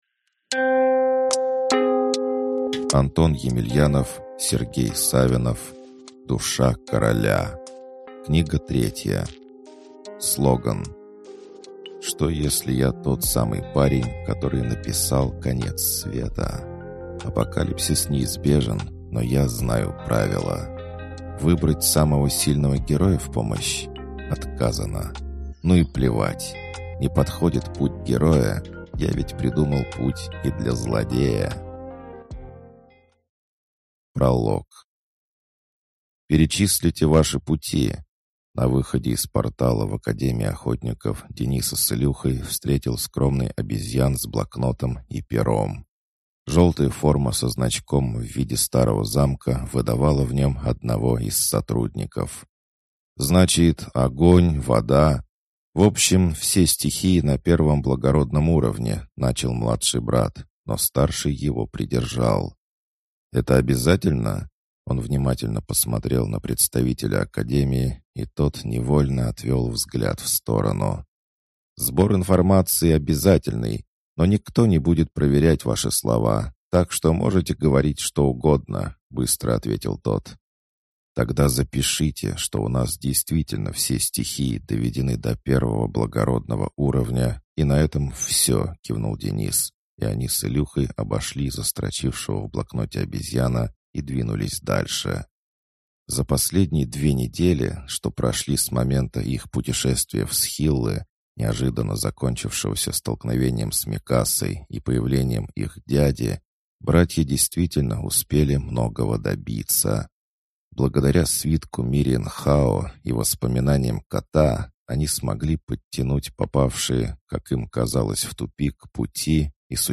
Аудиокнига Душа короля. Книга 3 | Библиотека аудиокниг